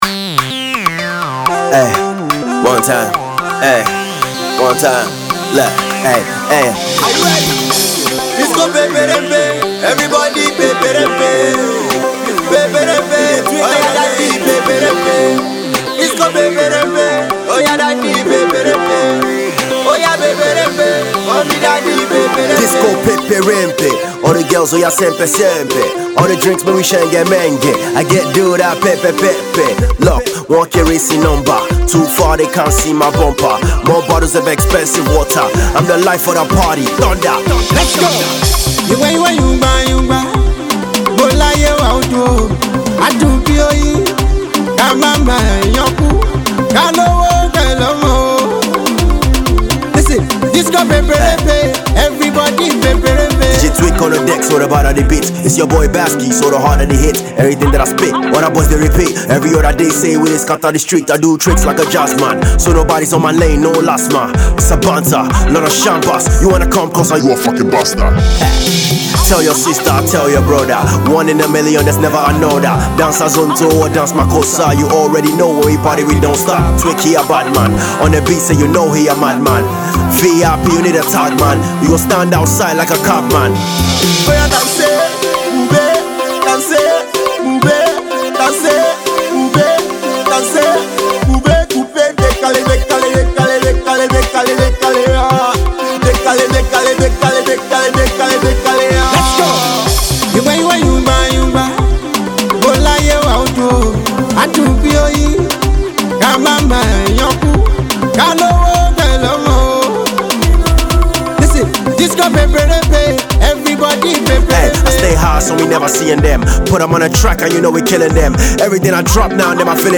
is Bass-heavy, playful, dance floor candy